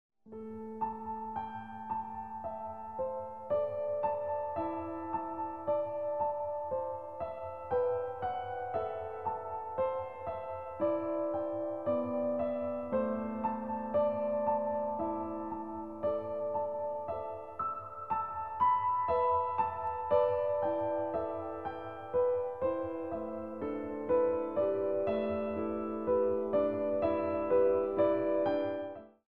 Long tracks of meditative music for stretch classes